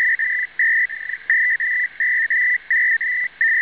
Vengono inviati 32 bit per ogni 'data frame', il ciclo completo ha una durata di 704 msec. di cui 256 msec. di trasmissione, 96 di pausa, 256 di ricezione, e altri 96 di pausa. DUP-ARQ ARTRAC A data-burst type synchronous duplex ARQ system using the ITA 2 alphabet. 5 characters are sent, made up of 5 bits per character, followed by 7 parity check bits.
A complete cycle has a duration of 704 msec and consists of 256 msec transmit, 96 msec pause, 256 msec receive, 96 msec pause. System will hop about in 400Hz steps to clear interference.